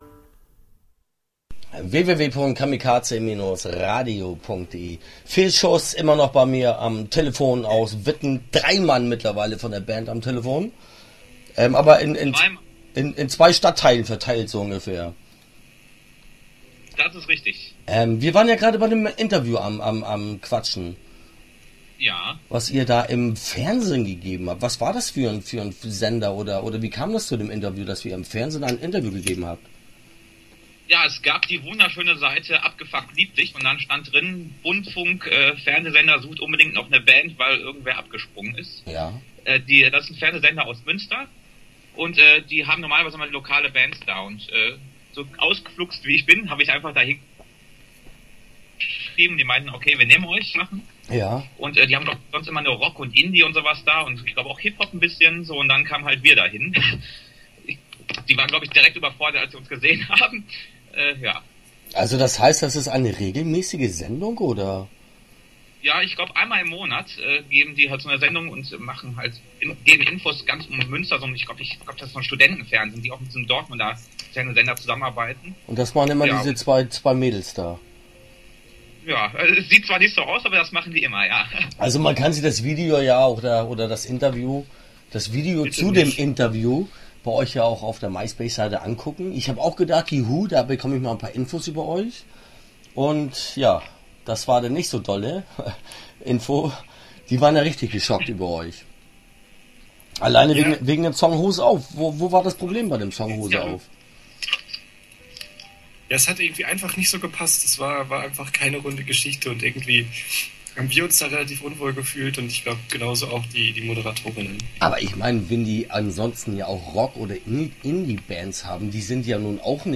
Interview Teil 1 (8:24)